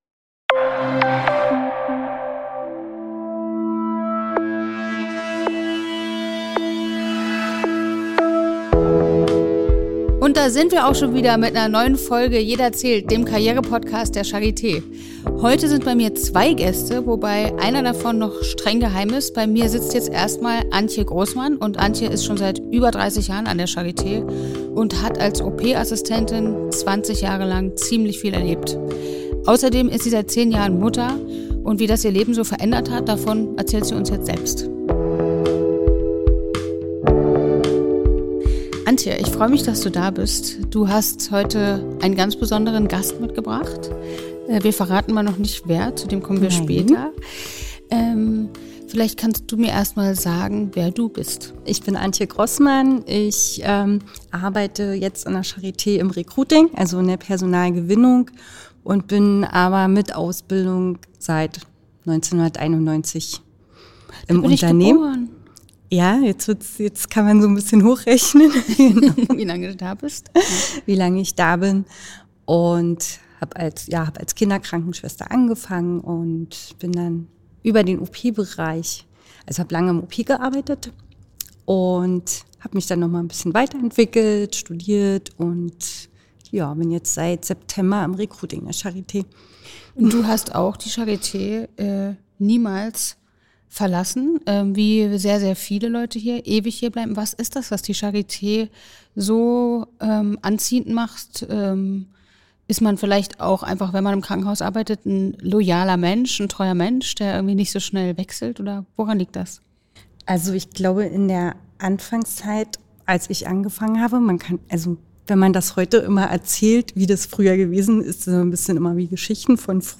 In Jede:r zählt spricht Schauspielerin Nina Gummich mit unseren Kolleginnen und Kollegen über ihren Alltag an der Charité, was sie täglich antreibt und wie es sich anfühlt in der Hauptstadt zu leben und zu arbeiten.